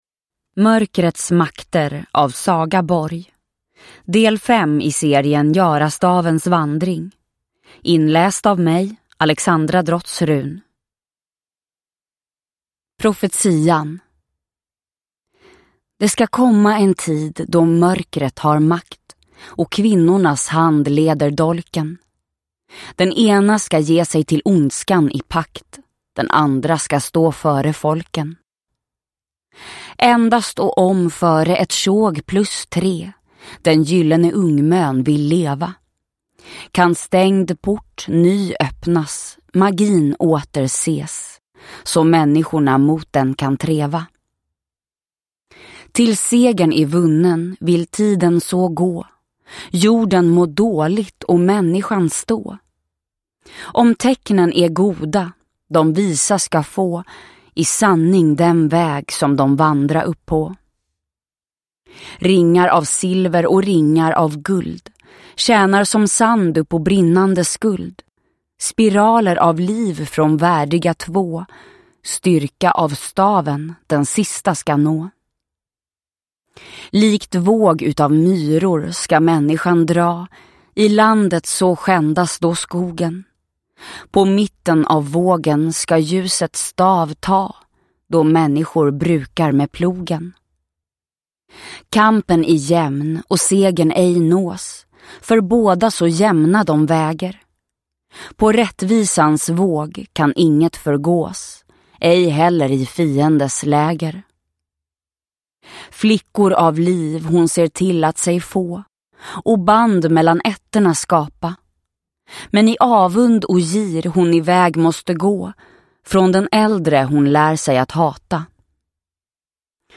Mörkrets makter – Ljudbok – Laddas ner